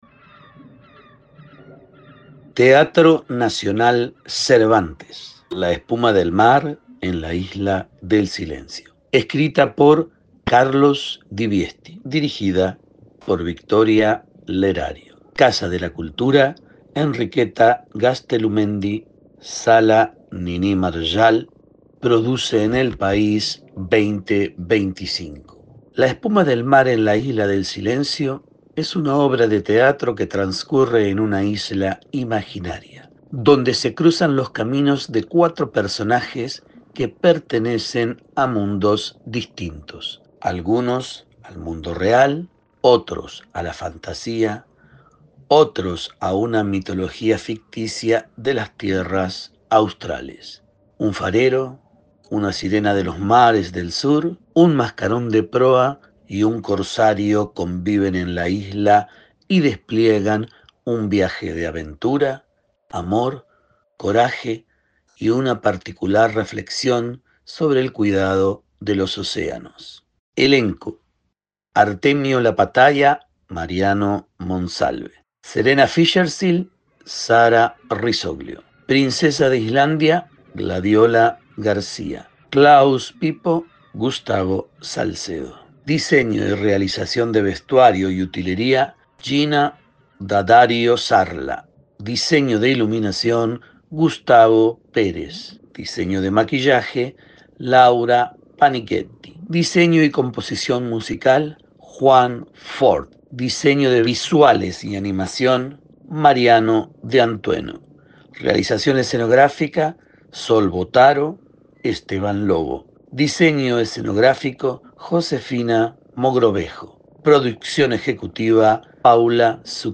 El siguiente audio corresponde a lectura del programa de mano del espectáculo La espuma del mar en la Isla del Silencio